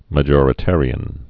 (mə-jôrĭ-târē-ən, -jŏr-)